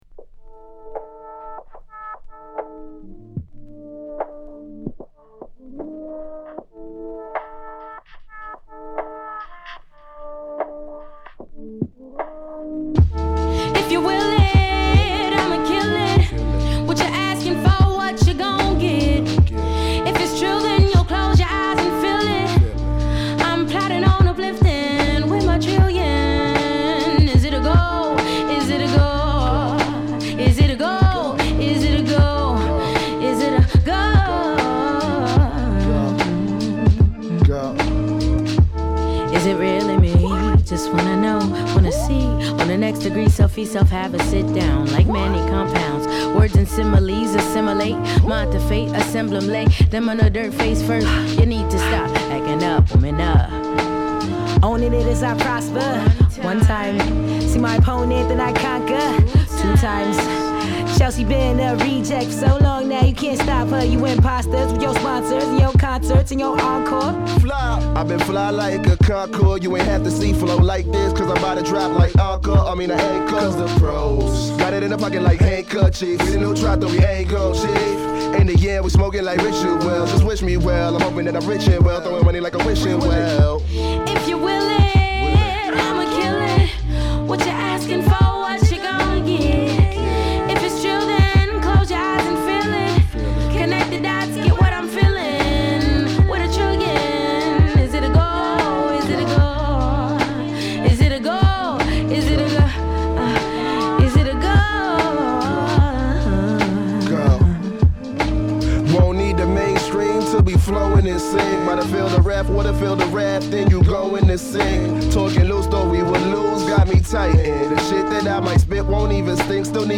気怠くジャジーなオリジナルver.に、リミックスver.のどちらも◎！...